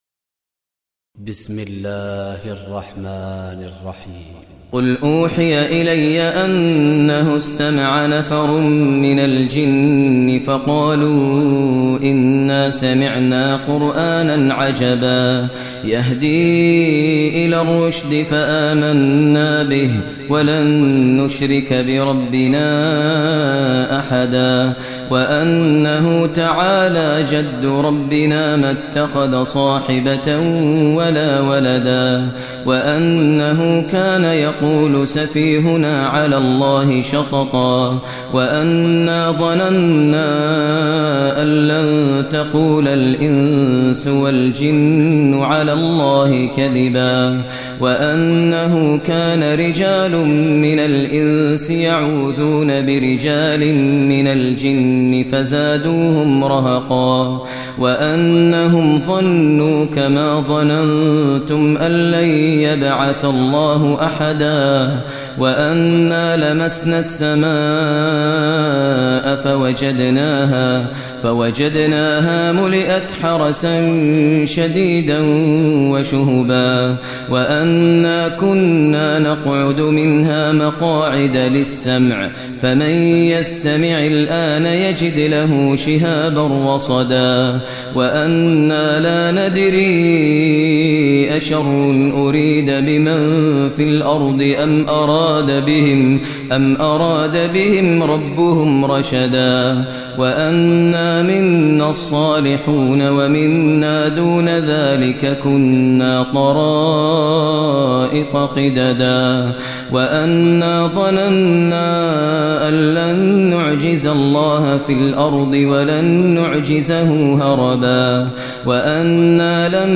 Surah Jinn, listen online mp3 tilawat / recitation in Arabic recited by Imam e Kaaba Sheikh Maher al Mueaqly.